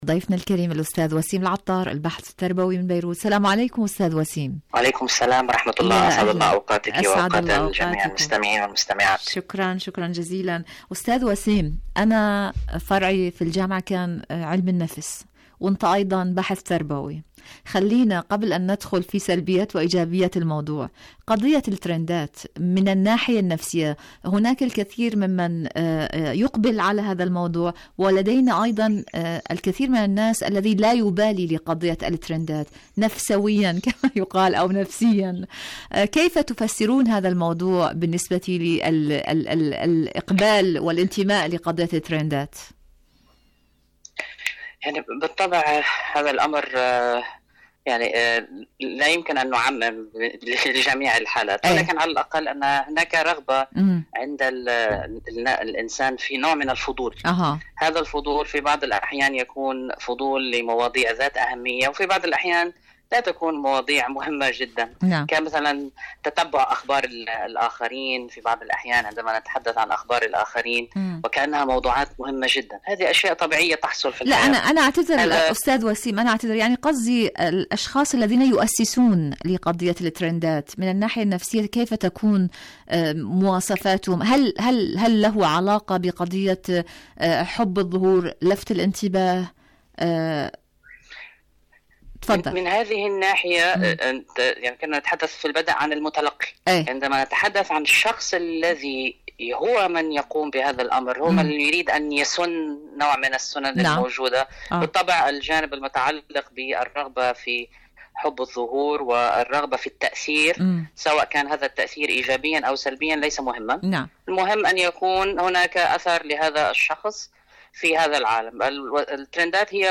مقابلات برامج إذاعة طهران العربية برنامج دنيا الشباب الشباب مقابلات إذاعية الشباب و حمى الترند ترند الشباب شاركوا هذا الخبر مع أصدقائكم ذات صلة حرب الإبادة والأمن الصهيوني المفقود..